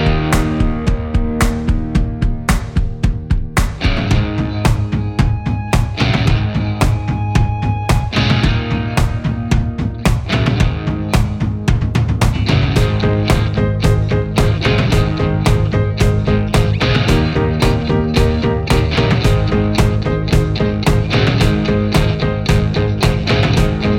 no Backing Vocals Country (Male) 2:31 Buy £1.50